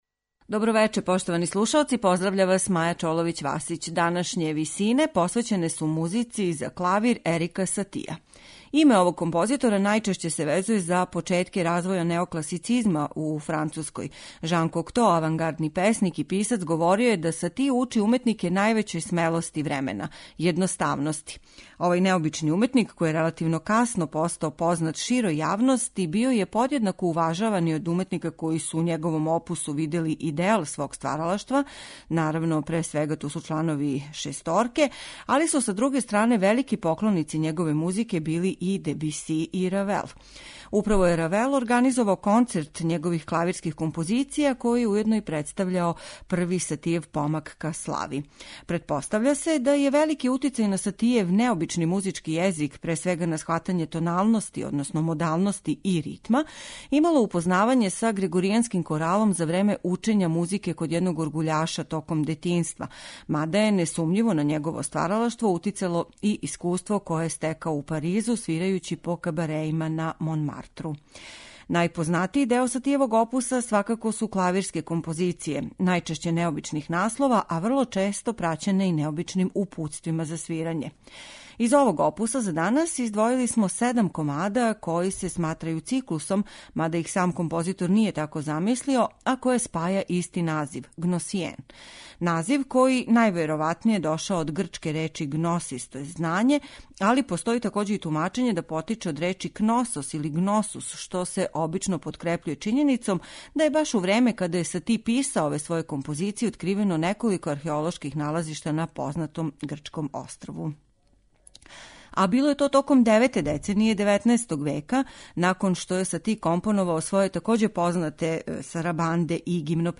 Музика за клавир Ерика Сатија
Настављајући се на „Гимнопедије", и комади из циклуса Гносиjен следе исту линију по питању темпа: споро, са изненађењем и поново споро.
и у мелодици и хармонским везама са снажним модални призвуком